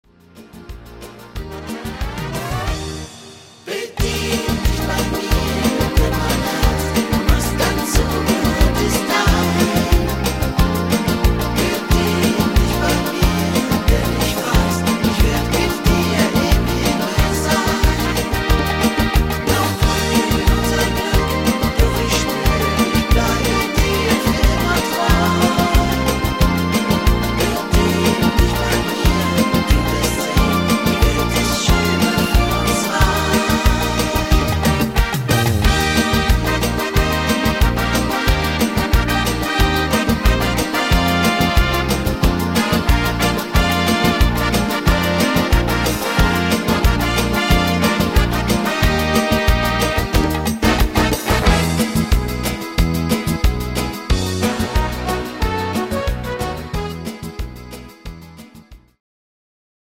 Rhythmus  Foxtrott
Art  Deutsch, Schlager 2020er, Tanzschule